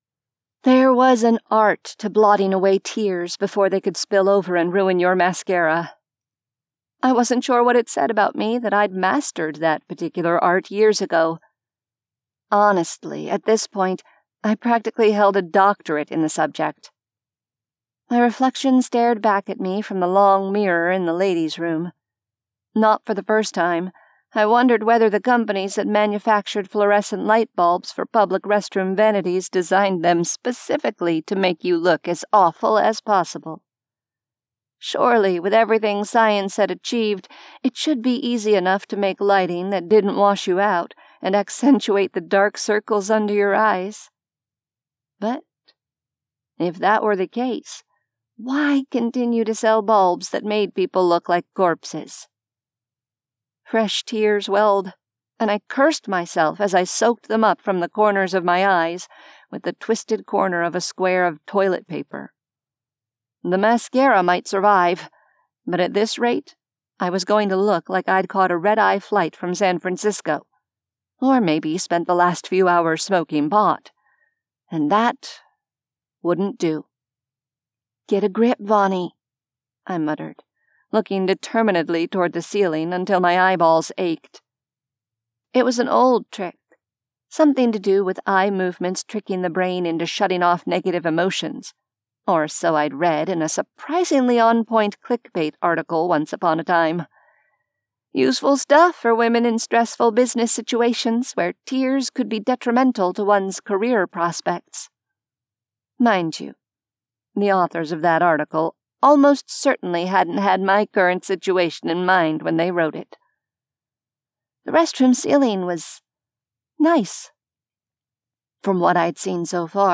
A sampling of audiobooks
Audiobook cover for Vampire Bound Complete Series by R.A. Steffan